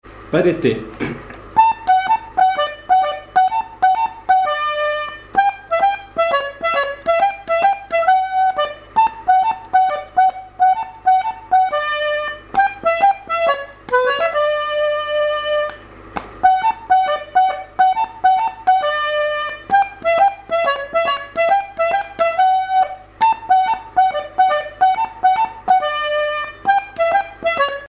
danse : pas d'été
airs de danse à l'accordéon diatonique
Pièce musicale inédite